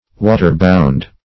\Wa"ter-bound`\